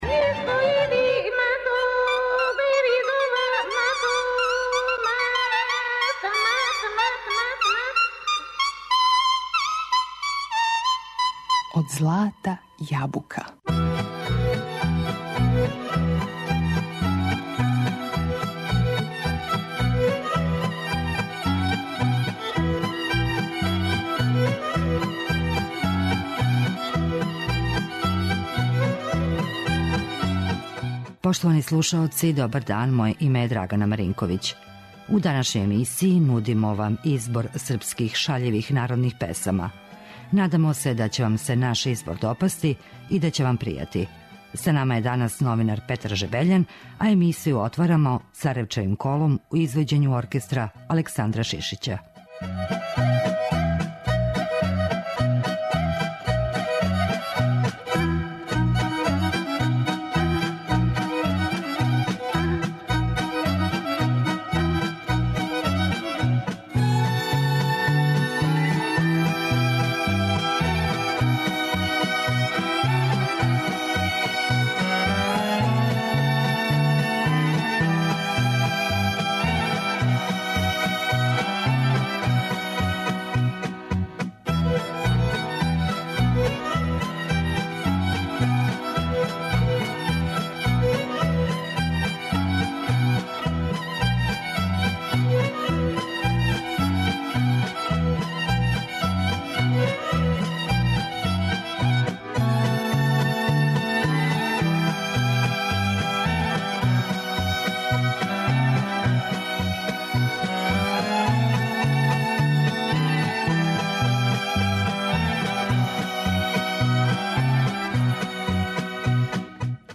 У данашњој емисији нудимо избор српских шаљивих народних песама.
Како у нашој певаној лирској мелодици у великохј мери преовлађују песме које казују о неком тужном, сентименталном осећању, о несрећној и неузвраћеној љубави, решили смо да се данас окренемо оним не тако честим песмама које су саздане од животне радости, духовите врцавости и шаљивог расположења.